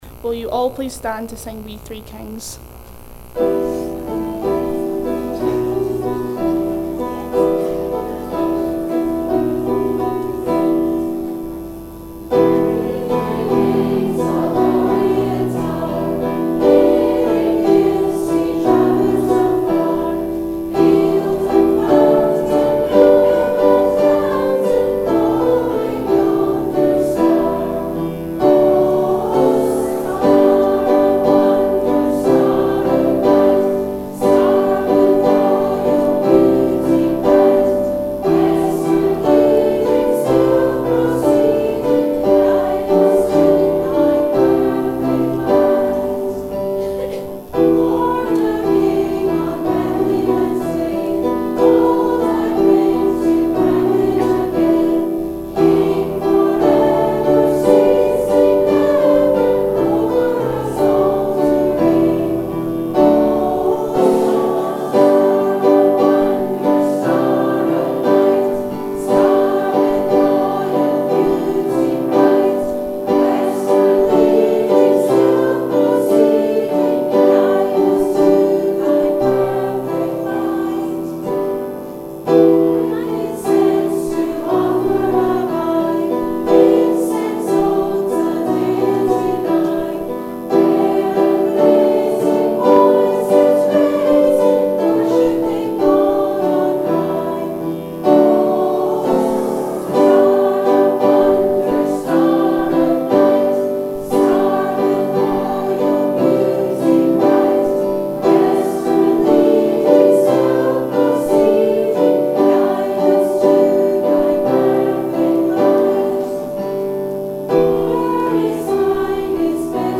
Click on the links to hear the carol singing and service
On Wednesday 5th of December St. Mungo's played host to Penicuik and Midlothian Girlguiding, Rangers, Brownies, Trefoil and Rainbows girls, parents and friends, for their annual Christmas Service.